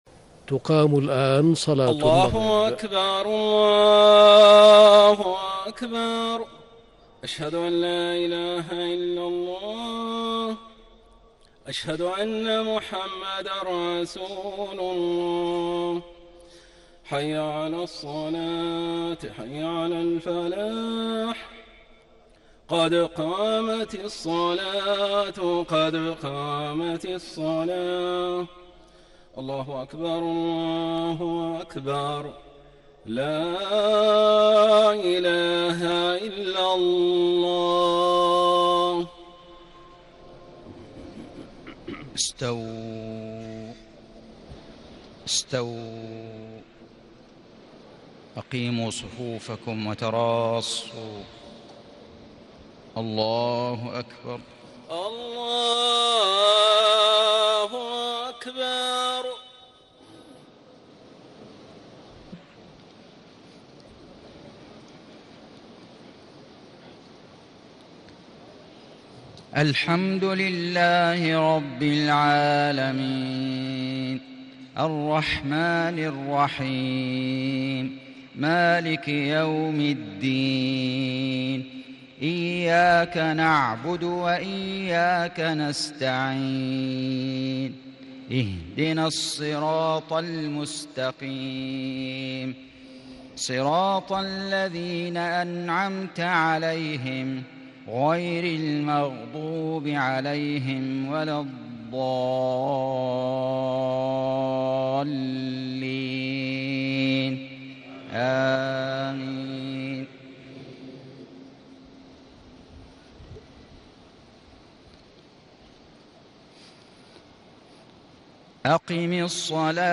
صلاة المغرب 17 رمضان 1437هـ من سورة الإسراء 78-84 > 1437 🕋 > الفروض - تلاوات الحرمين